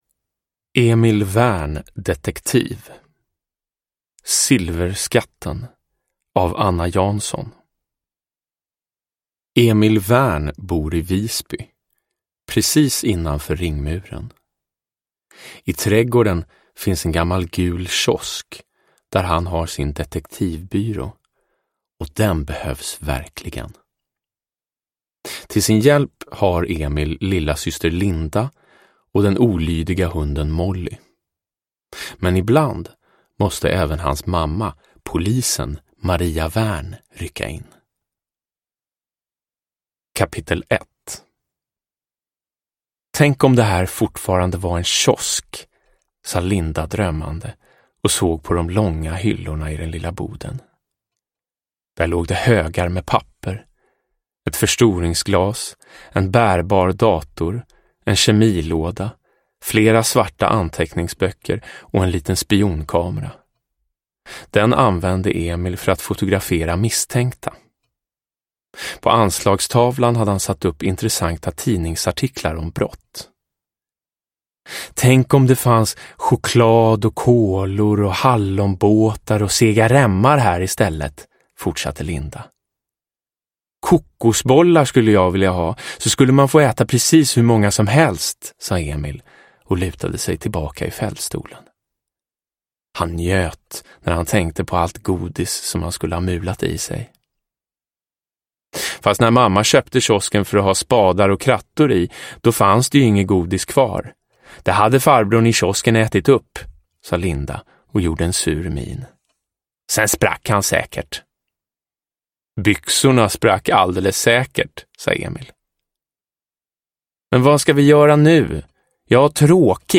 Silverskatten – Ljudbok
Uppläsare: Jonas Karlsson